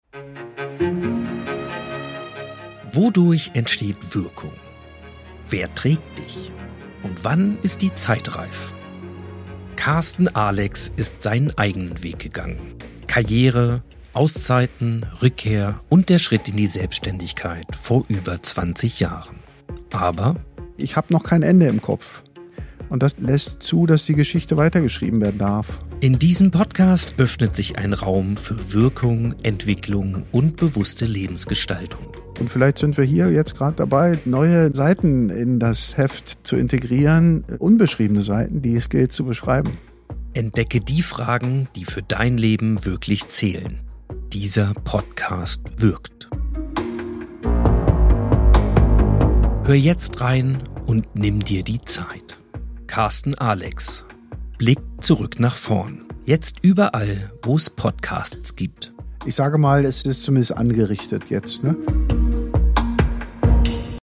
Trailer
Gespräche über Haltung, Wirkung und Leben